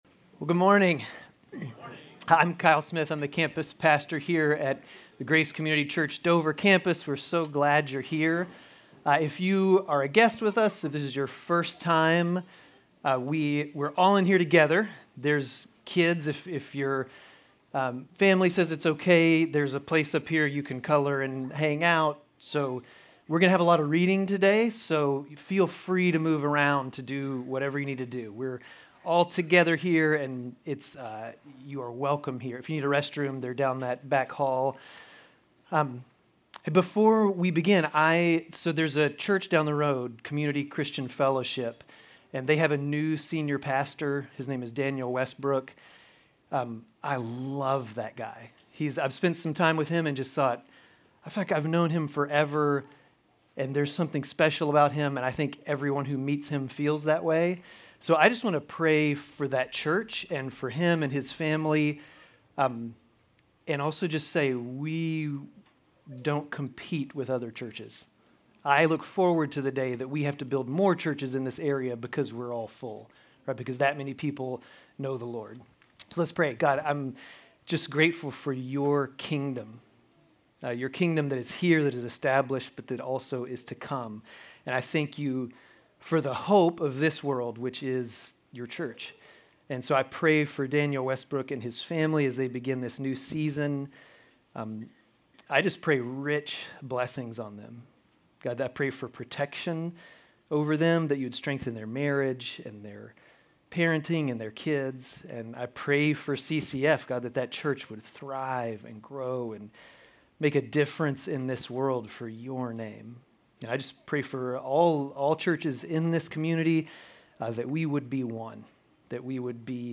Grace Community Church Dover Campus Sermons Jan 19 - Dover Campus Jan 05 2025 | 00:43:45 1x 00:00 / 00:43:45 Subscribe Share RSS Feed Share Link Embed